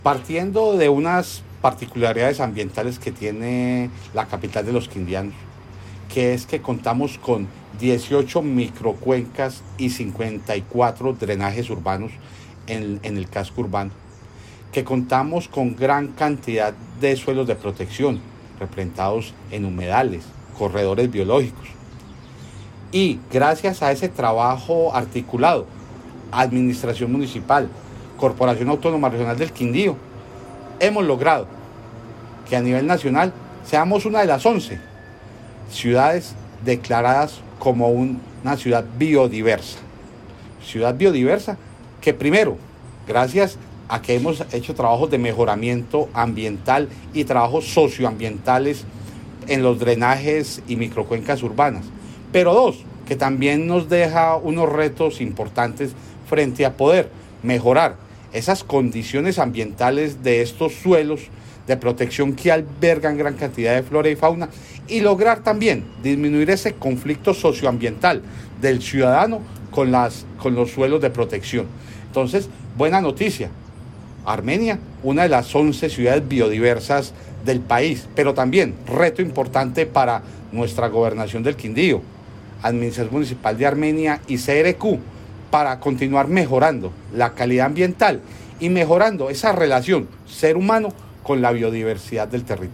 Audio de: José Manuel Cortés Orozco -Director General de La CRQ